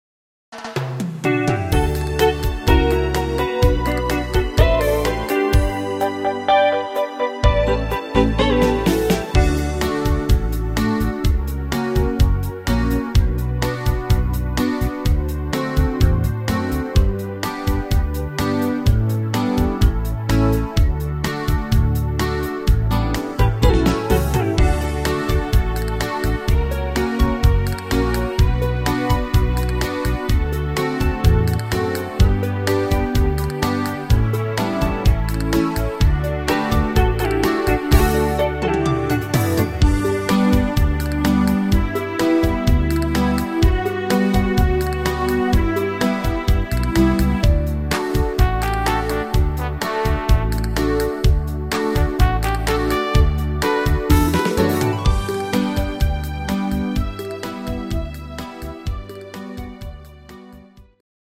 Buy -- Playback abmischen Buy